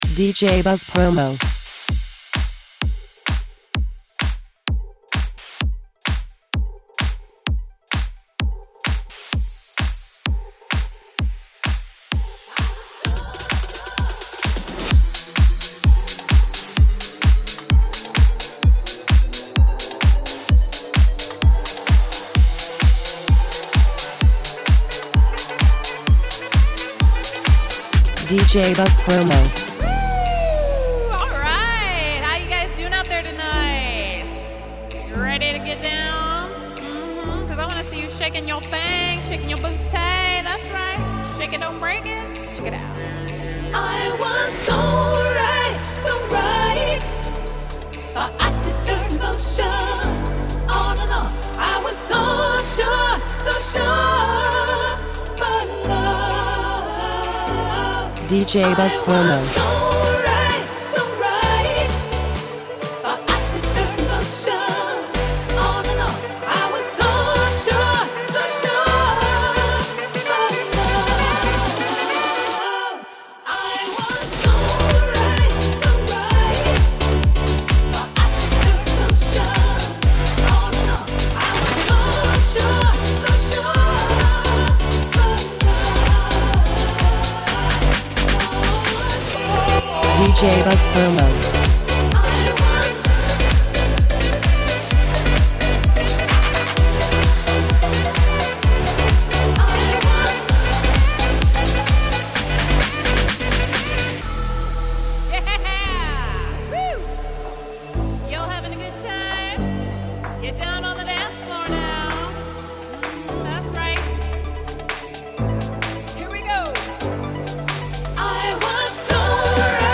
70s and 80s Dance Music